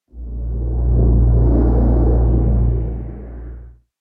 sounds / ambient / cave
cave9.mp3